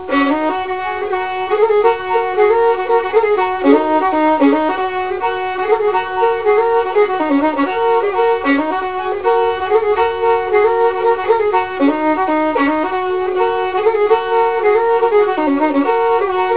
has a great deal more syncopation than most versions. For example, the down beat is deemphasized in measures 3, 5, and 7.